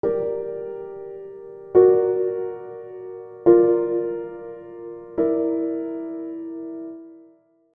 Chords: Gm - F#aug - Bb - Gm6
Despite their inherently unstable sonic character, augmented chords fulfill crucial functions within the harmonic landscape.